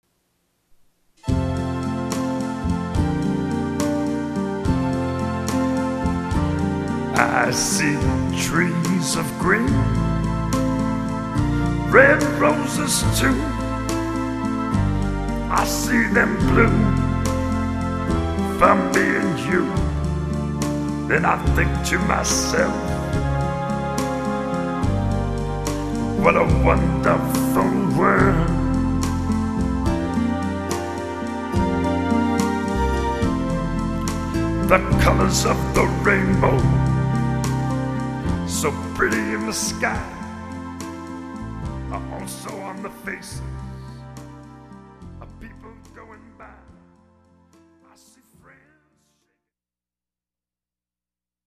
60's Music